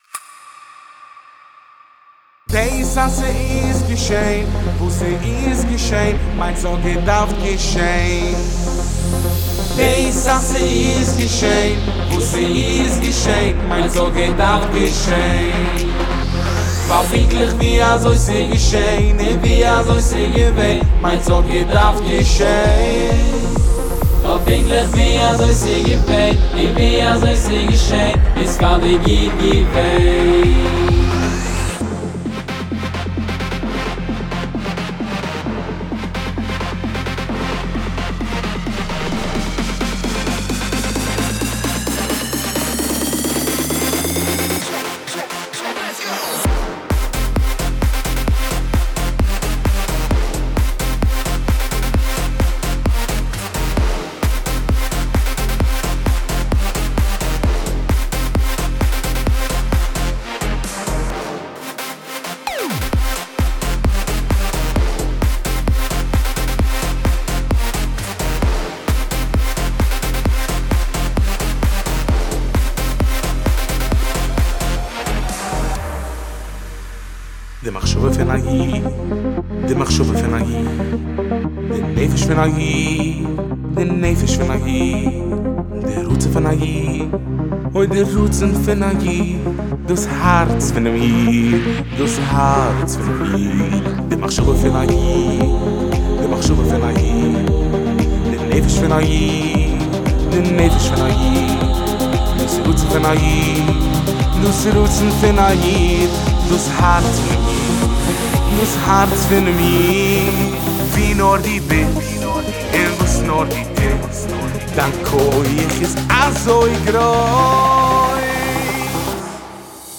ואוו ואוו המשחקים עם הריוורב.